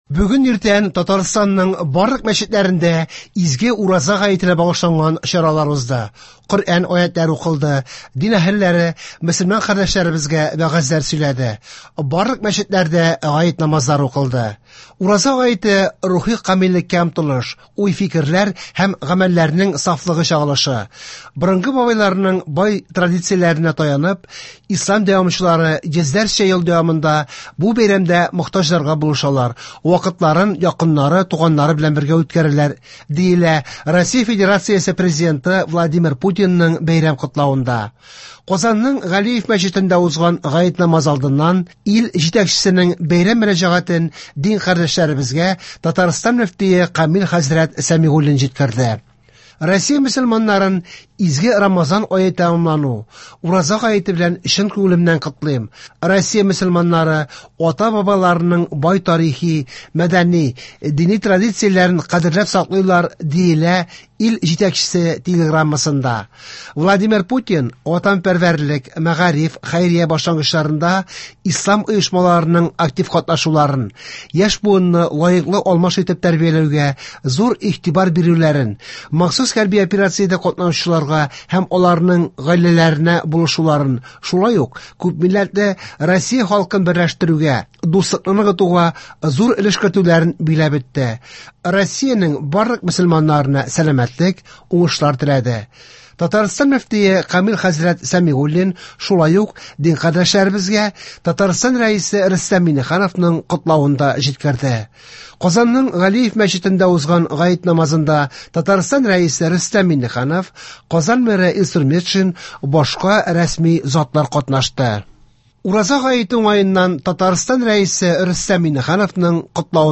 Яңалыклар (10.04.24)